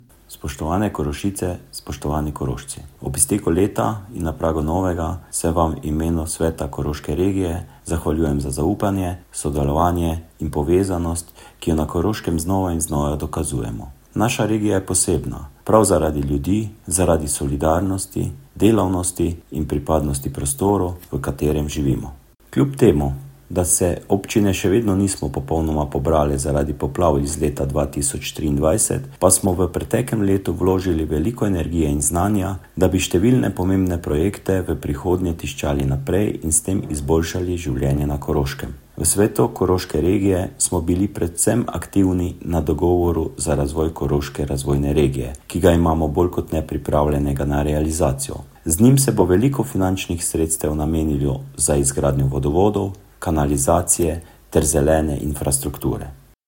Novoletna poslanica predsednika Sveta Koroške regije Mirana Pušnika